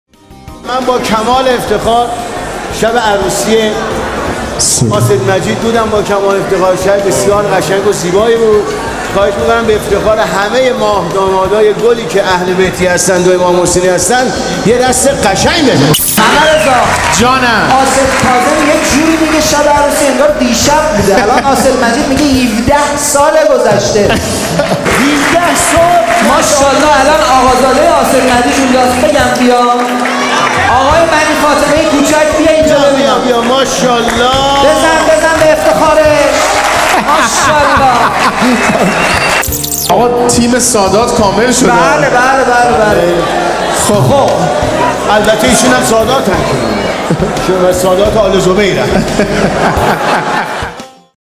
سید مجید بنی فاطمه سالروز ازدواج حضرت علی(ع)وحضرت زهرا(س) 12 مرداد 98 جلسه یا زهرا(س)قم